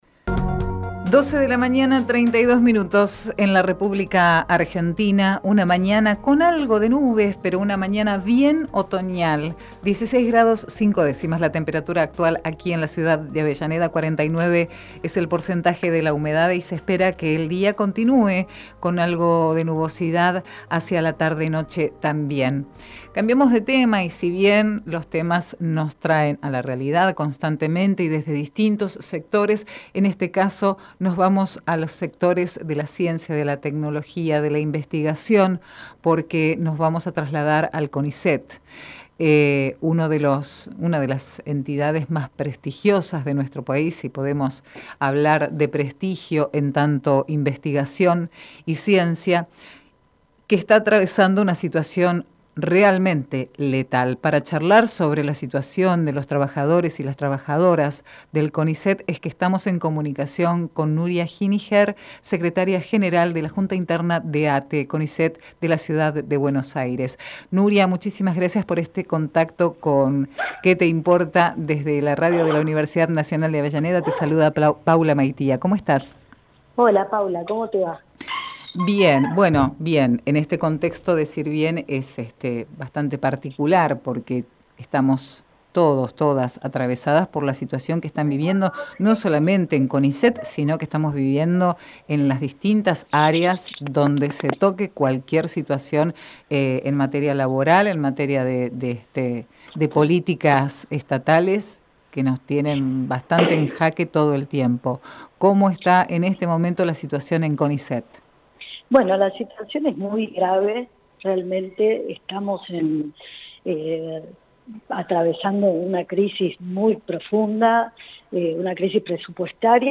Compartimos entrevista realizada en "Que te Importa"